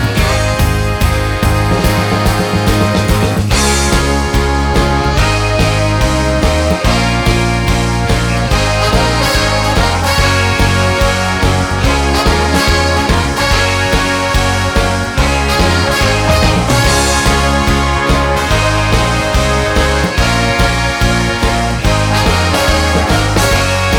no Backing Vocals Jazz / Swing 3:18 Buy £1.50